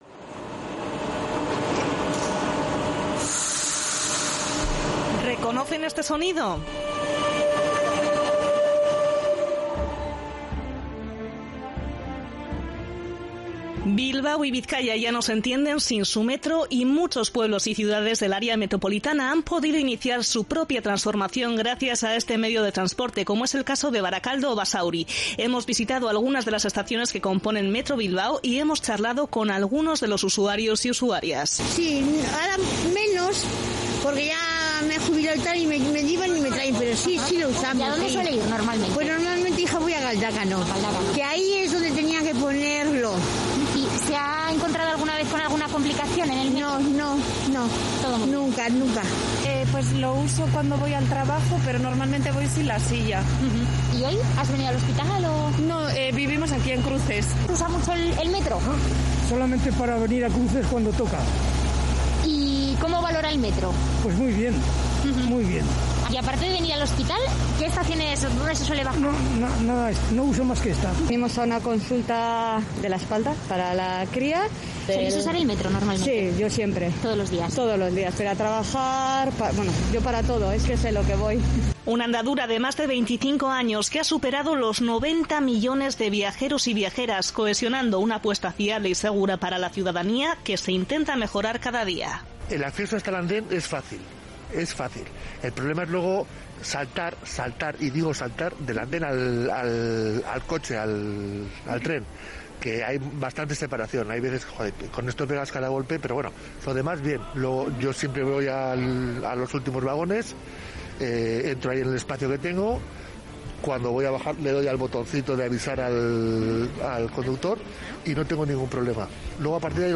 Entre ellas, la expansión de una red que comenzó con la puesta en marcha de la Línea 2, y que continuará con la progresiva conexión de puntos clave del territorio de Bizkaia. En Onda Vasca realizamos un recorrido por las estaciones de Metro Bilbao para testar qué opinan las personas usuarias sobre este servicio.